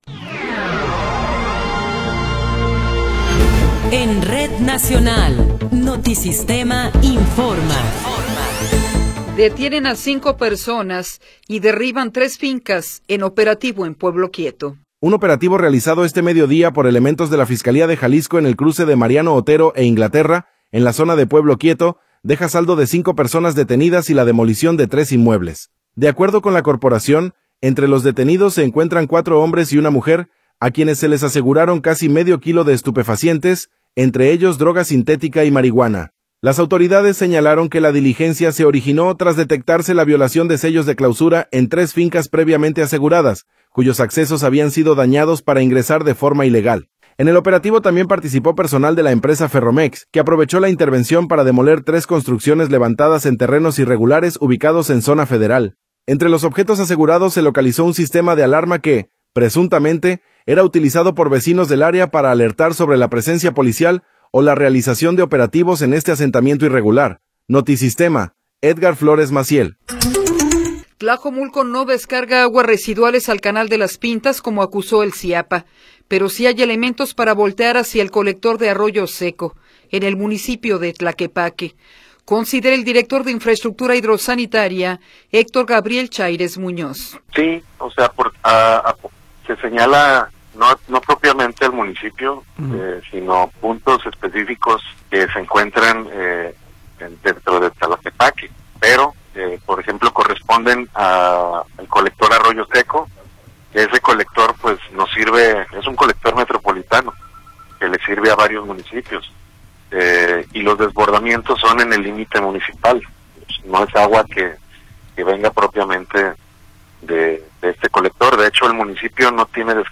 Noticiero 15 hrs. – 11 de Marzo de 2026
Resumen informativo Notisistema, la mejor y más completa información cada hora en la hora.